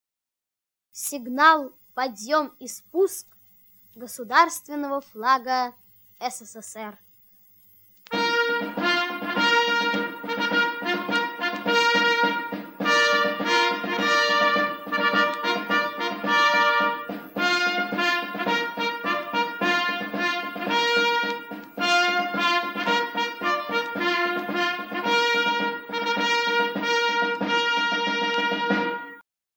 Марш - "Торжественный вынос знамени"